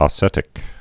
(ŏ-sĕtĭk)